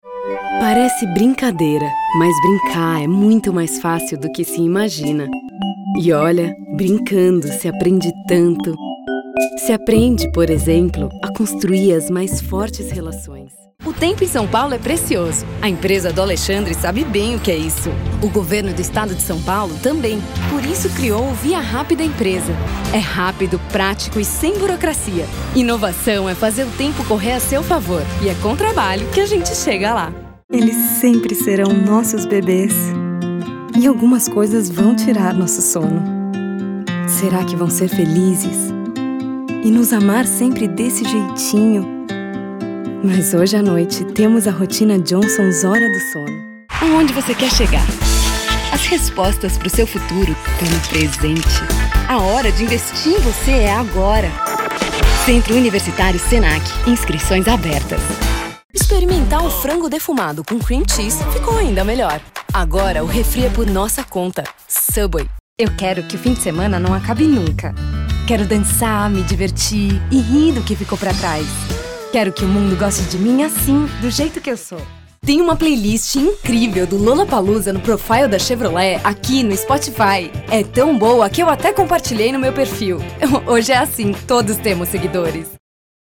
Feminino
Locução Natural
Voz Jovem 01:26